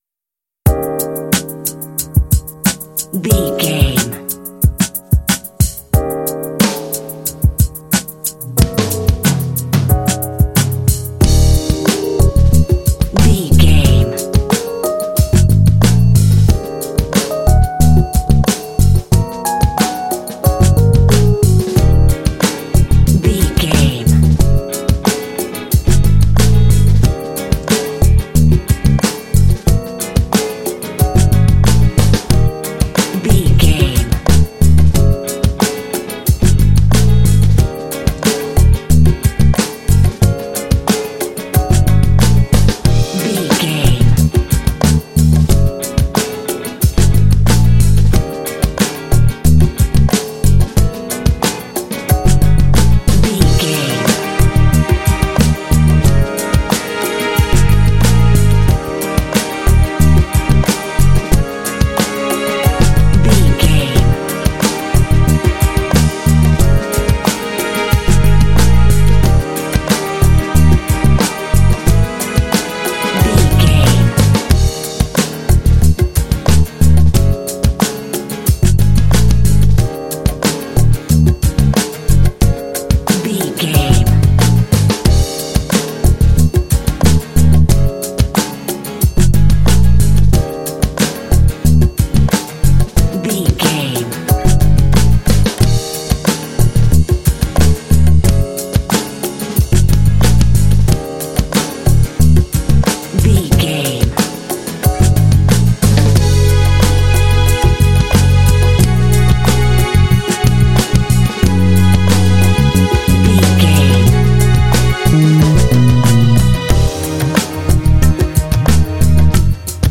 Uplifting
Aeolian/Minor
E♭
funky
groovy
smooth
drums
piano
bass guitar
electric piano
percussion
strings
Lounge
downtempo